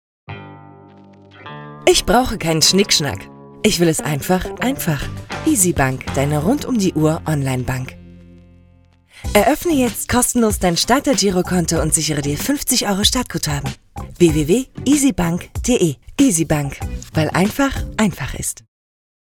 2018_Werbung_EBank.mp3